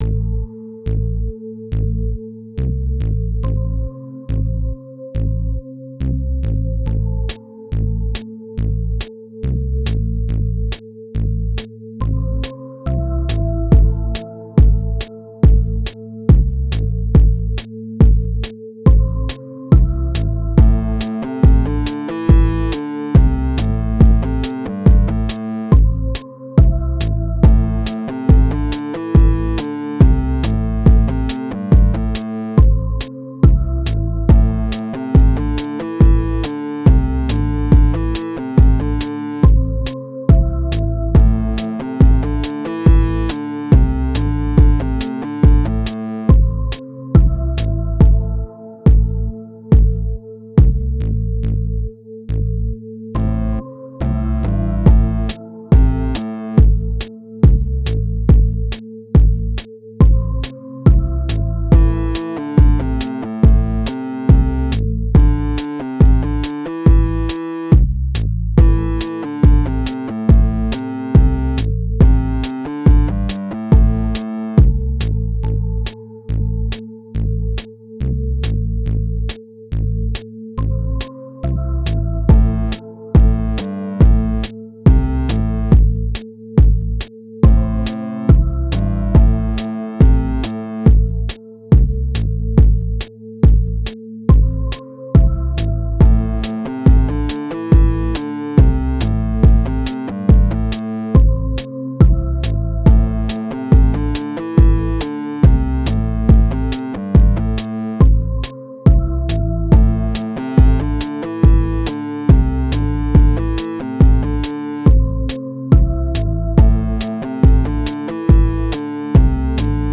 A rock instrumental song I made for my free